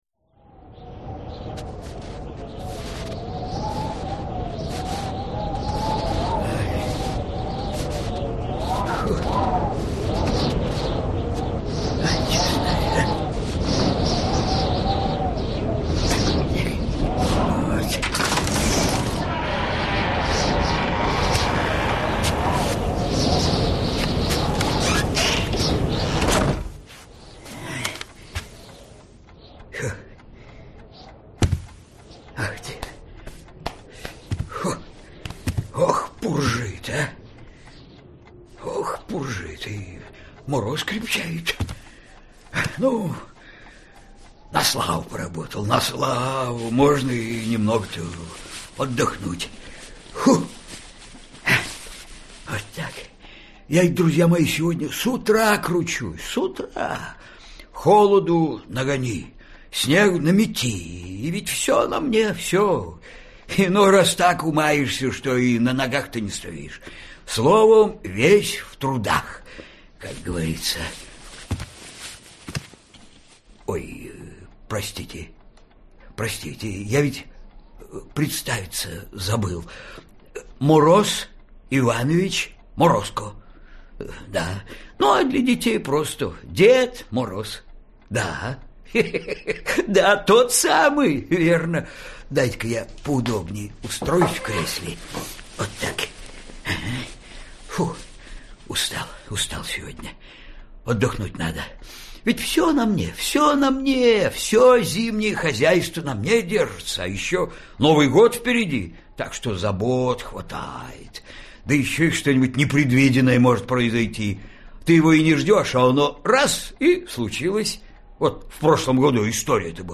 Аудиокнига Новогодние каникулы Вани Огородникова | Библиотека аудиокниг